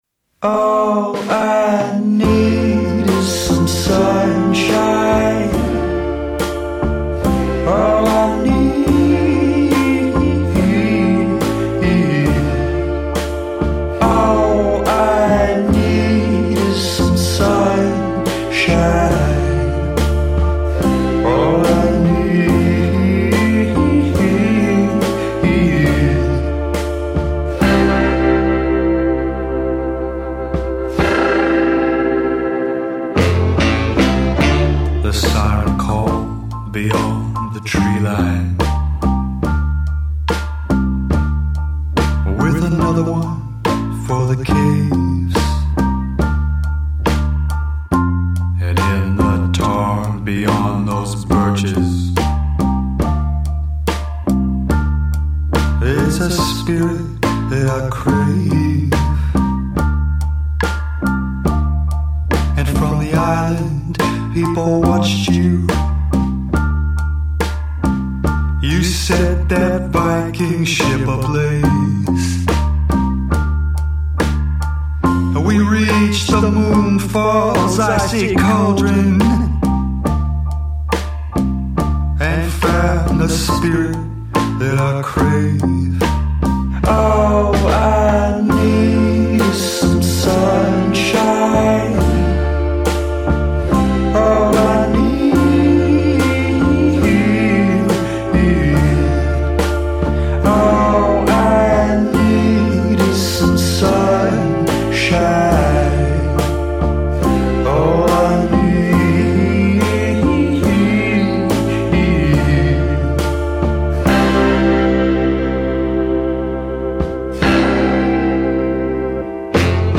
Documentaries